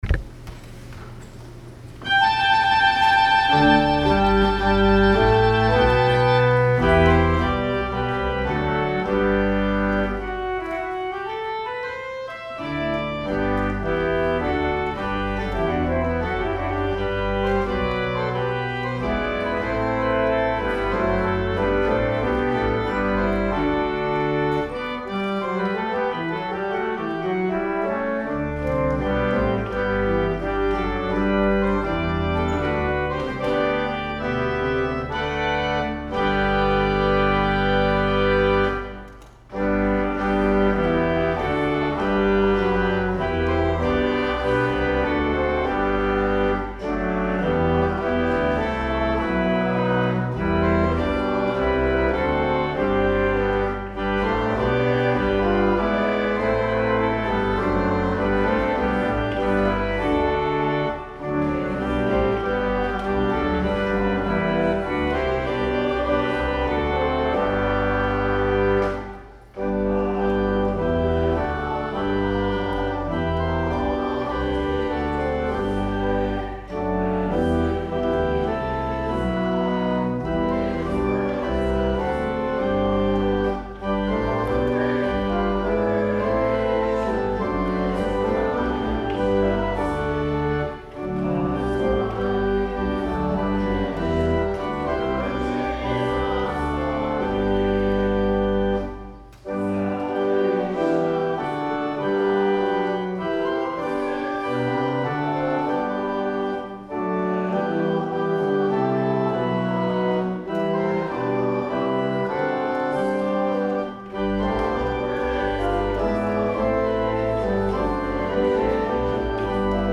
Music from May 5, 2019 Sunday Service
Final hmyn and Postlude Improvisation